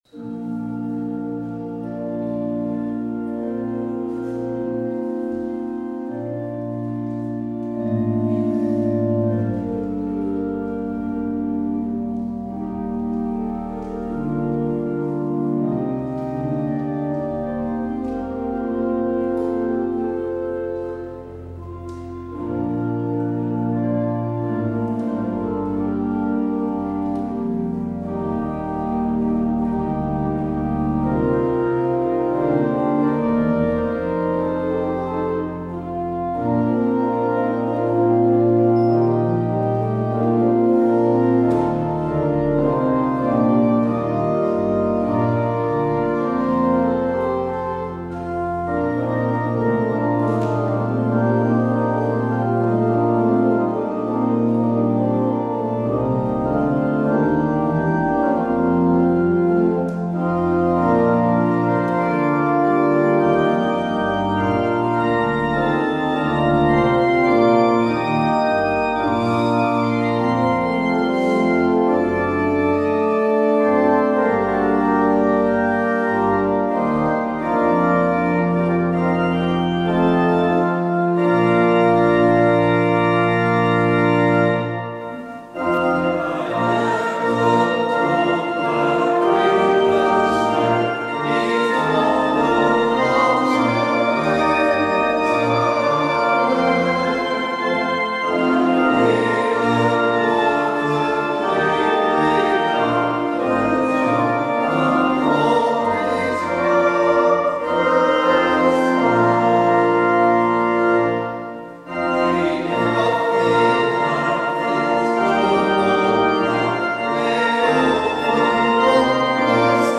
 Luister deze kerkdienst hier terug: Alle-Dag-Kerk 18 april 2023 Alle-Dag-Kerk https
Het openingslied is Lied 641: 1 en 2. Als slotlied hoort u Lied 630: 1 en 2 Sta op! Een morgen.